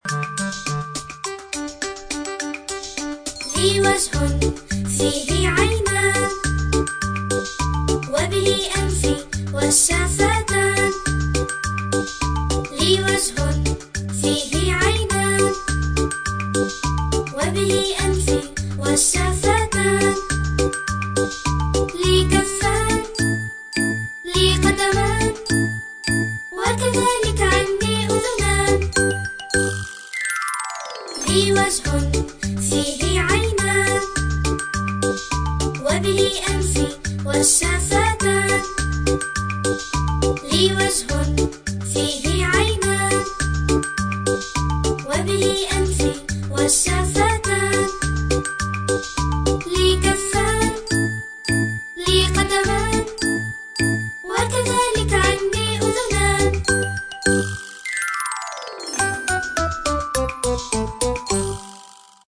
Arapça Çocuk Şarkısı MP3 Dinle